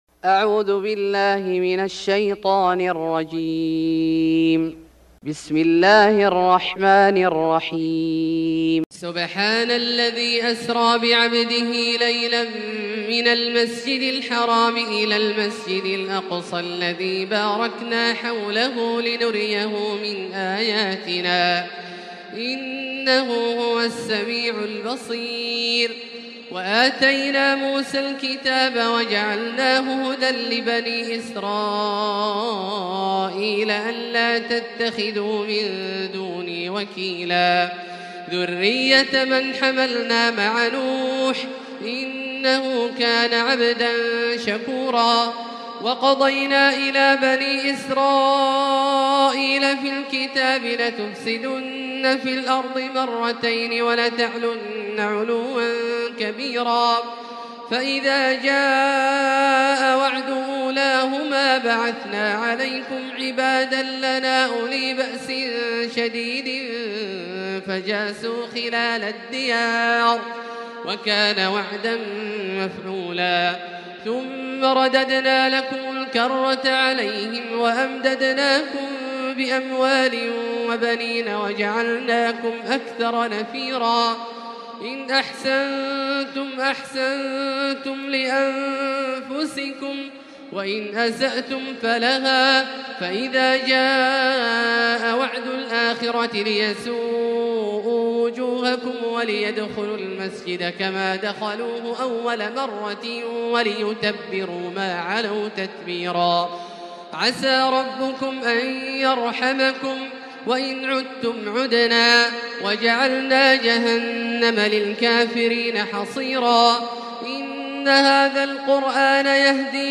سورة الإسراء Surat Al-Isra > مصحف الشيخ عبدالله الجهني من الحرم المكي > المصحف - تلاوات الحرمين